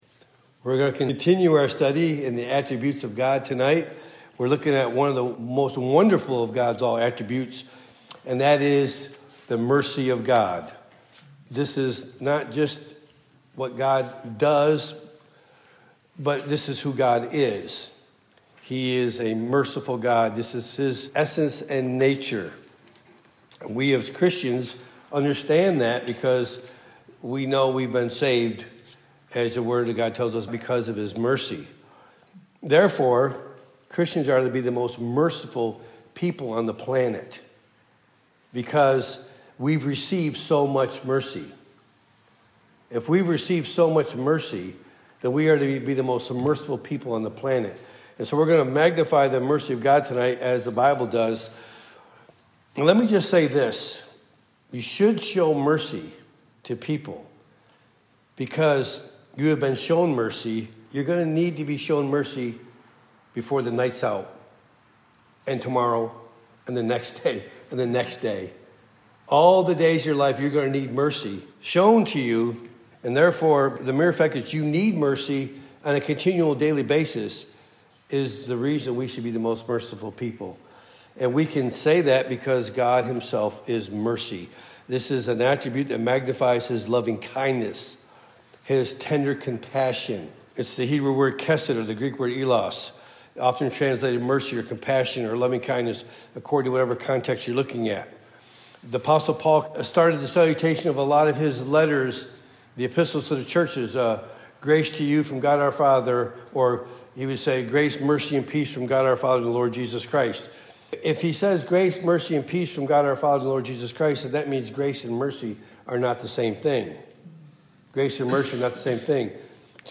Topic: Wednesday Pastoral Bible Study